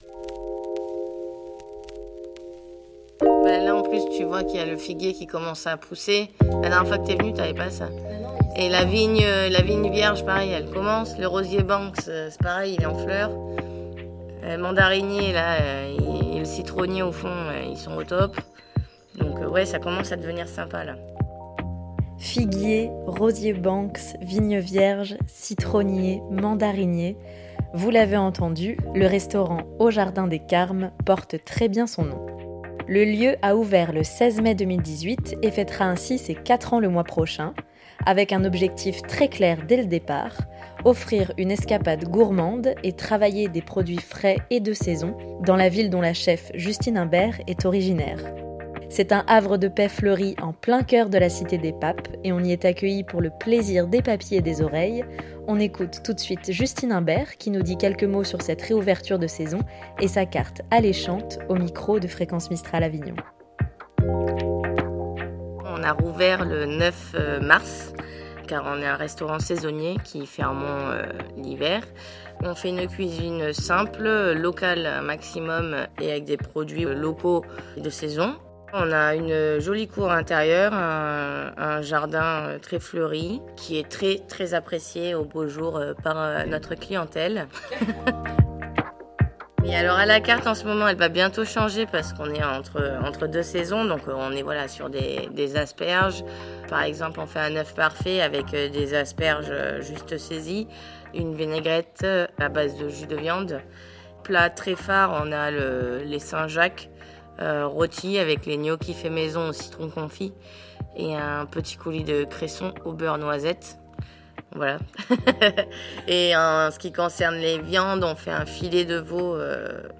au micro de Fréquence Mistral Avignon